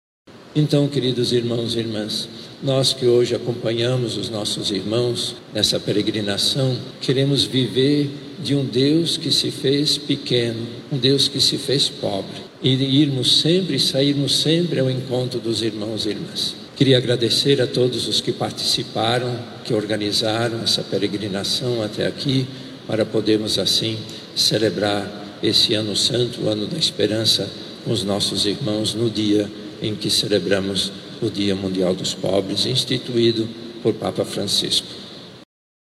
Durante a Santa Missa, o cardeal Leonardo Steiner ressaltou que ao celebramos este jubileu se renova o compromisso de uma Igreja em saída, com os olhos voltados para os pobres da sociedade, levando o Evangelho da Misericórdia a todos os cantos.
Sonora-cardeal.mp3